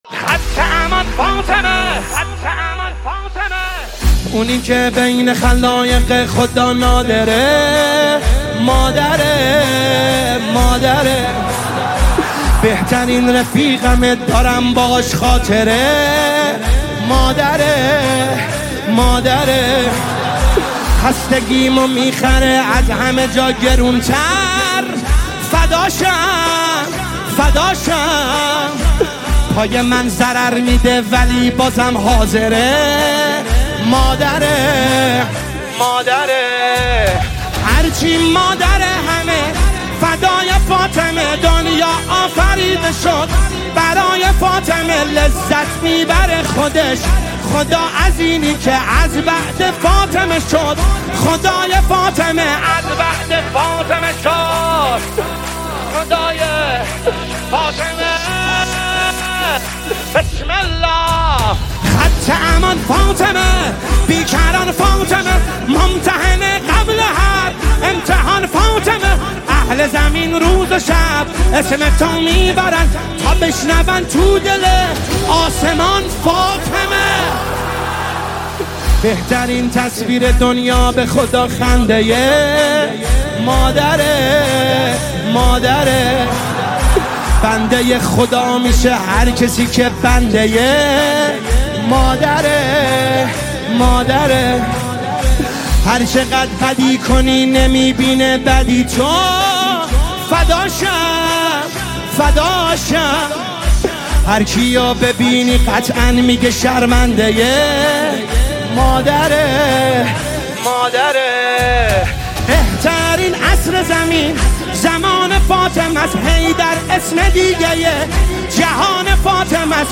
نماهنگ دلنشین
به مناسبت ایام فاطمیه